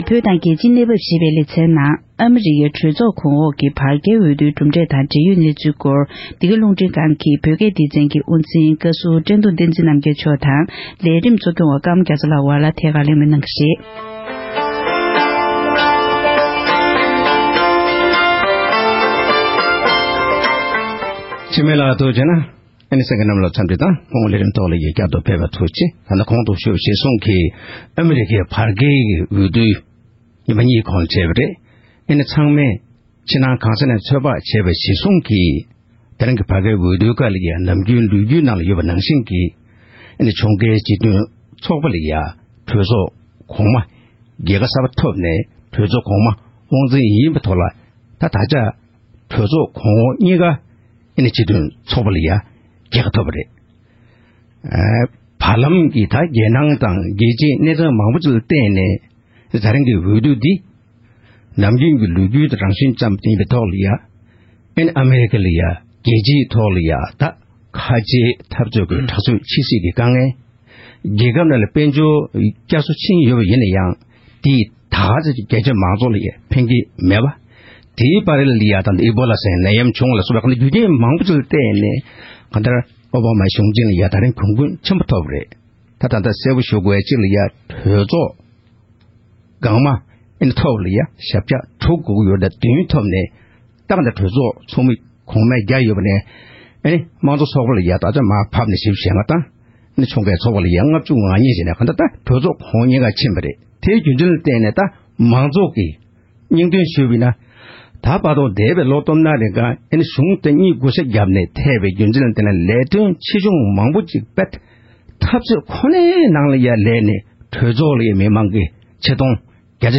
ཨ་མེ་རི་ཀའི་རྒྱལ་ཡོངས་གྲོས་ཚོགས་གོང་འོག་གི་བར་མའི་འོས་བསྡུ་དང་འབྲེལ་བའི་དཔྱད་གླེང༌།